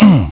Worms speechbanks
jump1.wav